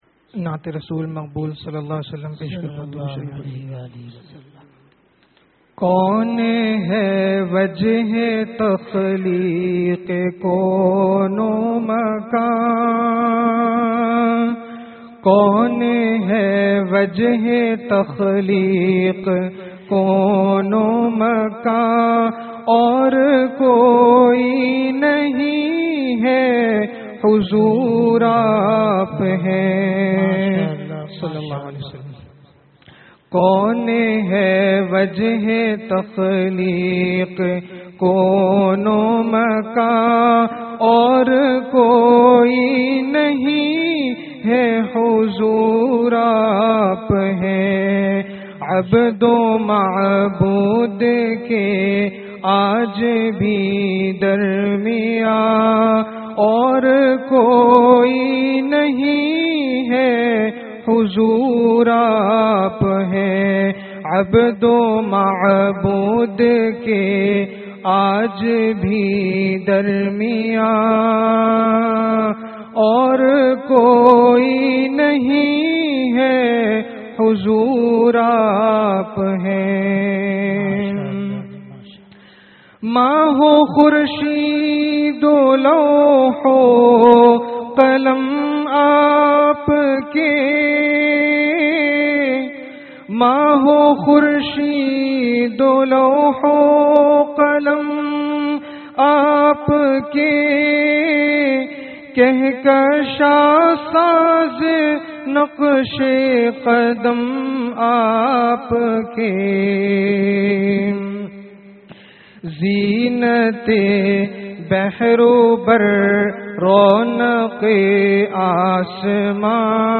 Bayanat
Waliden ki khidmat or hamari zimme dari (bad jummah byan)